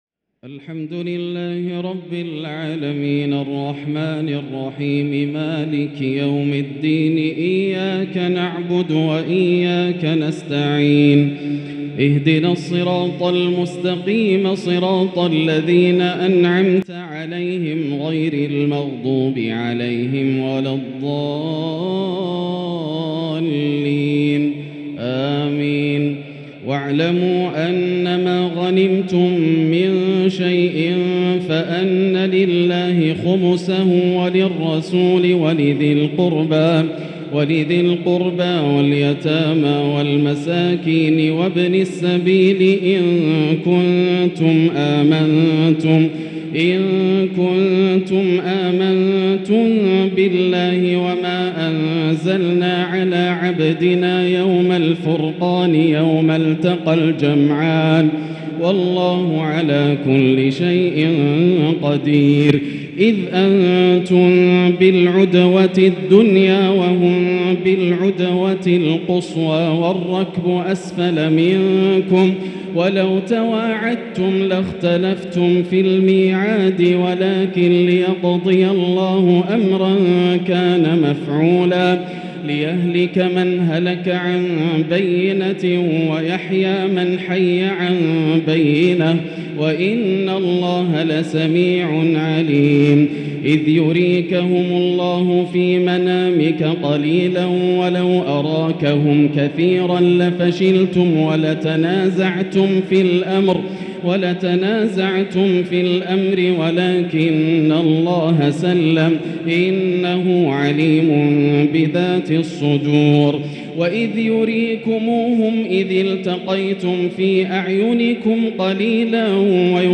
تراويح ليلة 13 رمضان 1444هـ من سورتي الأنفال (41) التوبة (1-35) |taraweeh 13st niqht Surah Al-Anfal and At-Tawba 1444H > تراويح الحرم المكي عام 1444 🕋 > التراويح - تلاوات الحرمين